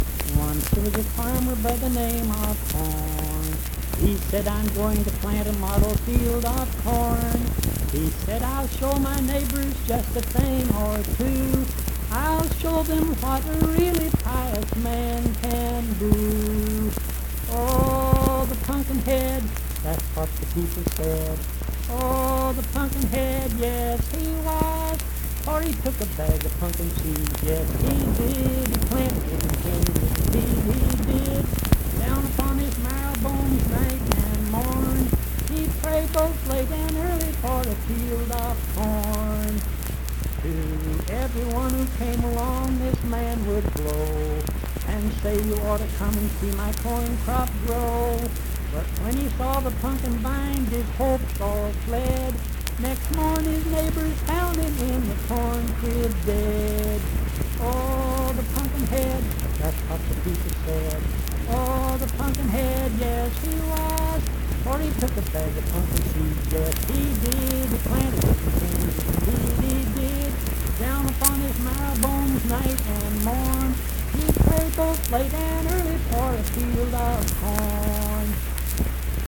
Accompanied (guitar) and unaccompanied vocal music
Performed in Mount Harmony, Marion County, WV.
Voice (sung)